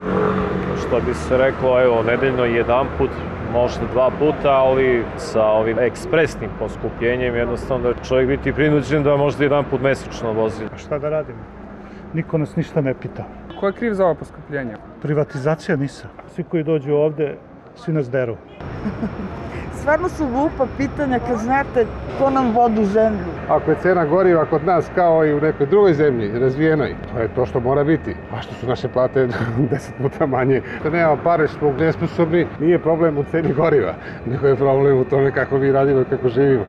Beograđani o poskupljenju goriva